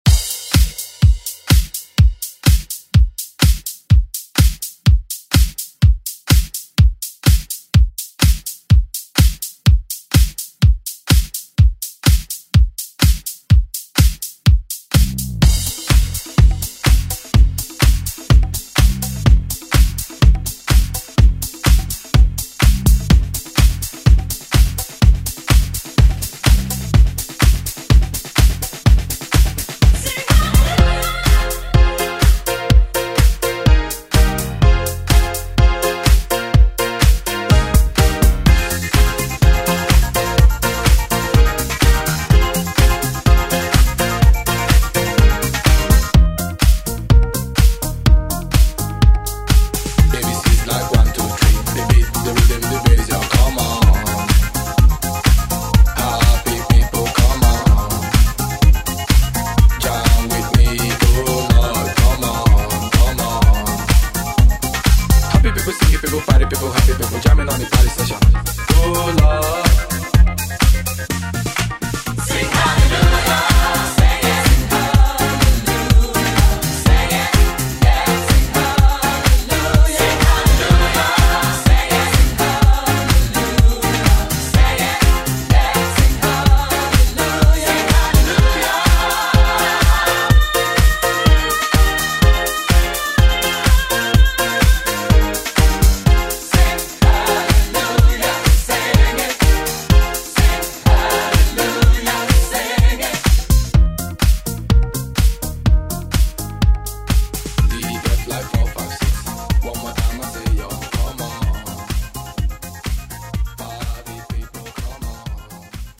Genres: 2000's , HIPHOP , R & B